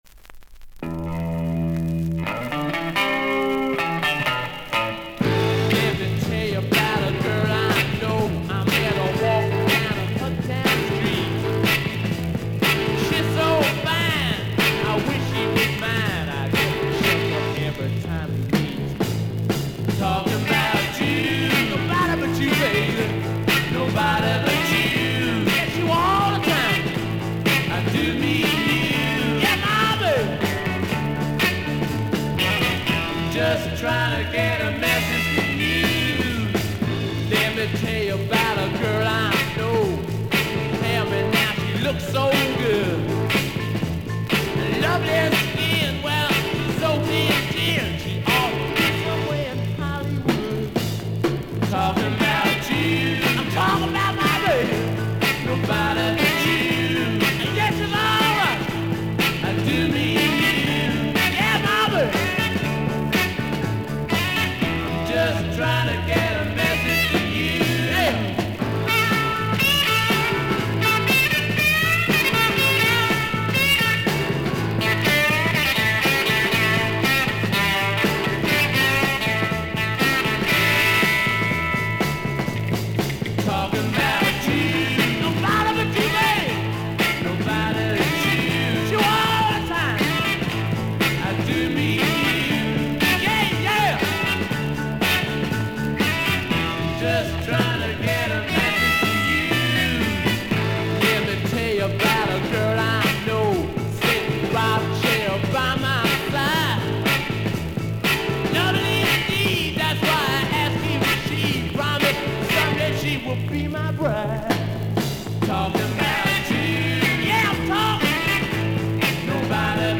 見た目ほどののノイズはありません。